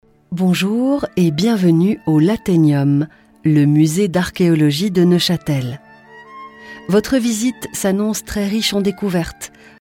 Catalogue : Audioguide